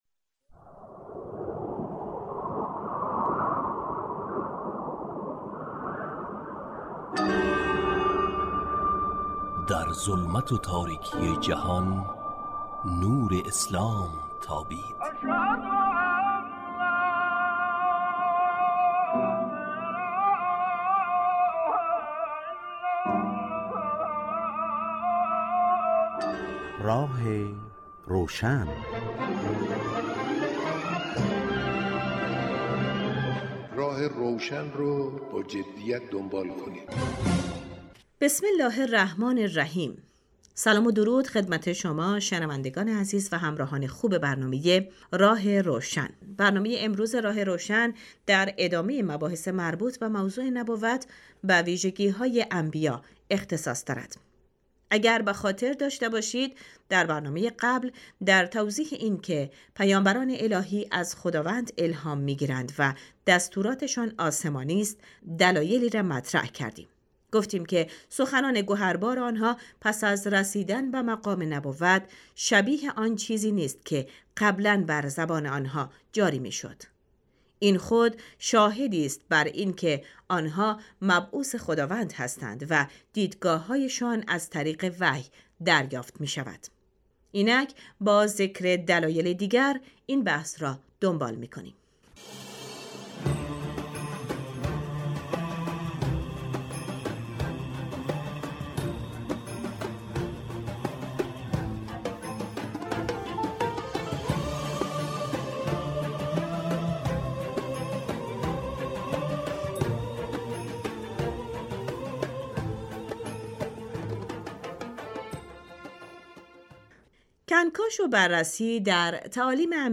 کارشناس : حجت اسلام قرائتی